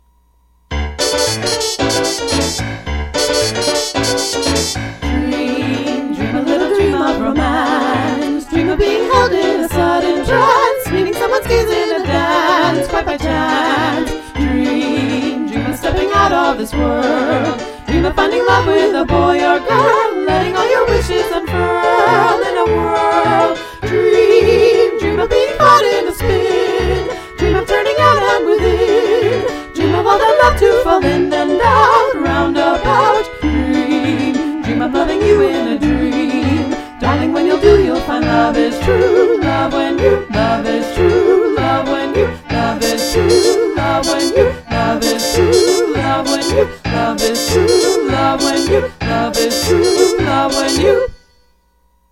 The song sounds like your average crooner love song from the era.
Into this discussion Chet Cheshire on the radio introduces the Tweedle Sisters singing an uptempo version of “Dream”.
And at the climax of the argument something strange happens: The Tweedle Sisters keep repeating “love is true love when you…” over and over until Jane finally takes the skipping needle off the revolving record on the phonograph.